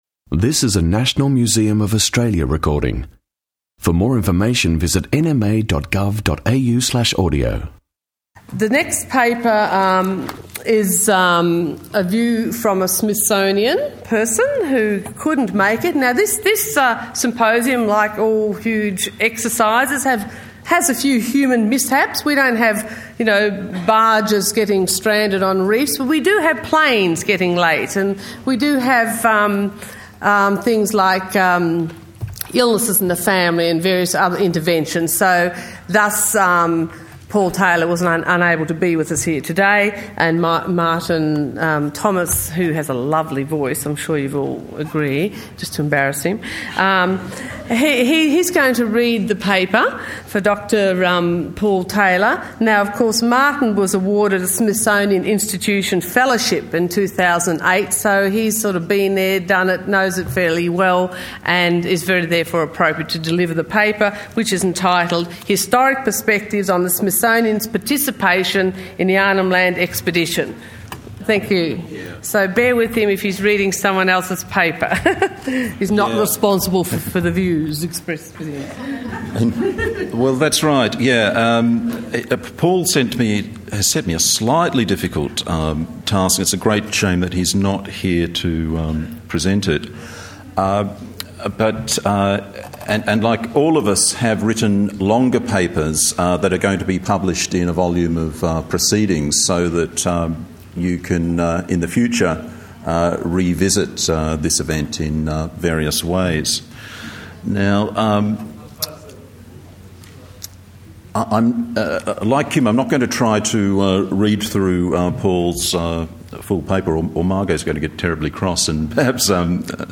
Barks, Birds and Billabongs symposium 17 Nov 2009